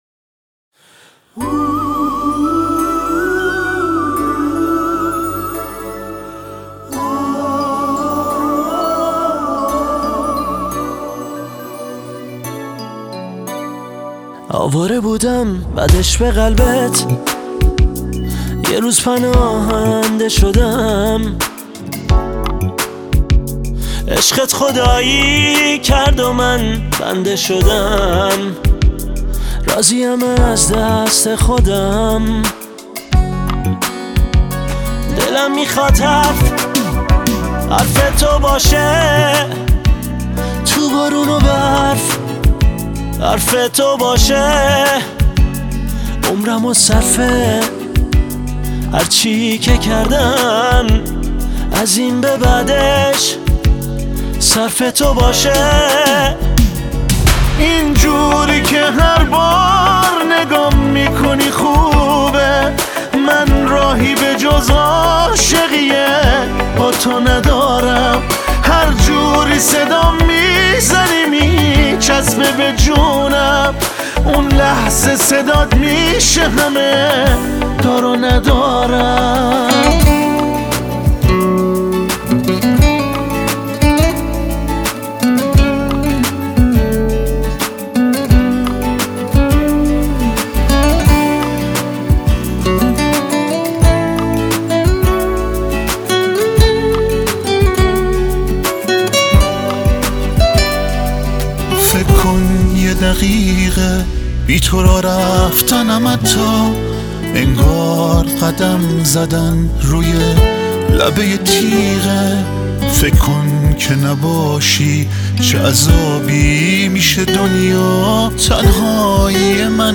یه آهنگ جذاب و عاشقانه
موسیقی پاپ